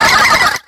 infinitefusion-e18/Audio/SE/Cries/SPINDA.ogg at a50151c4af7b086115dea36392b4bdbb65a07231